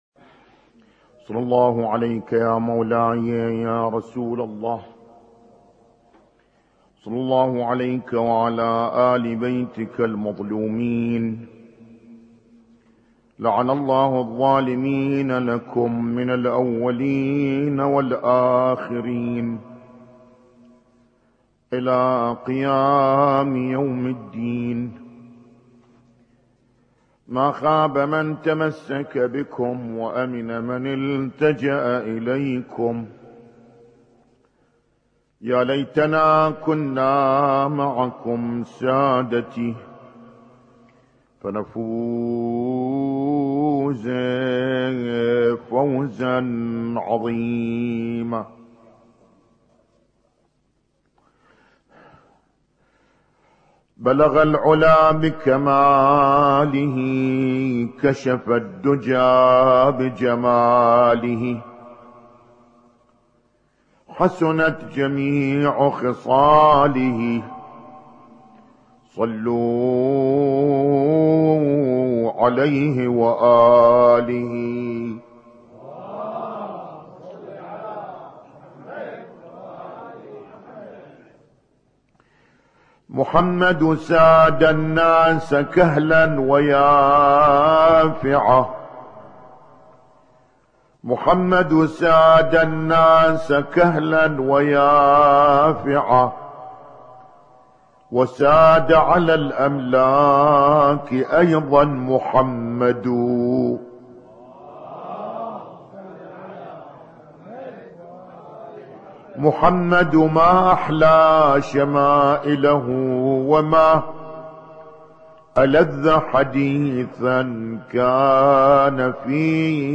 اسم التصنيف: المـكتبة الصــوتيه >> المحاضرات >> المحاضرات الاسبوعية ما قبل 1432